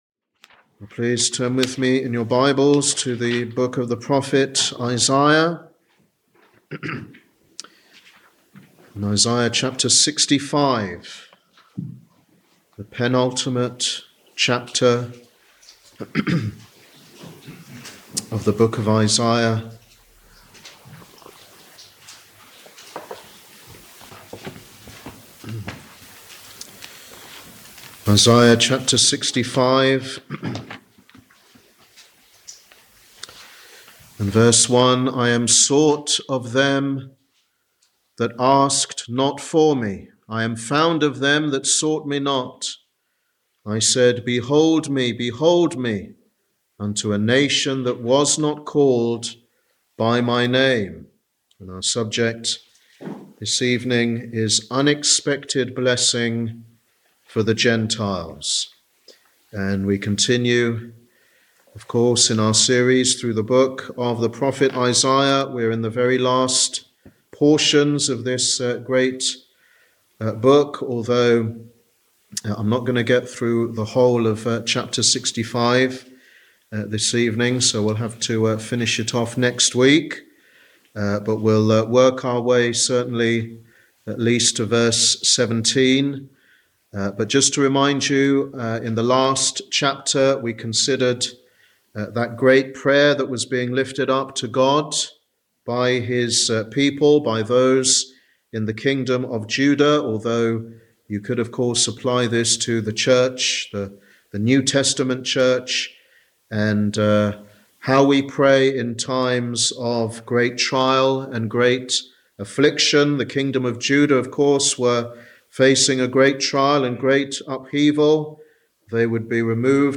Wednesday Bible Study
Sermon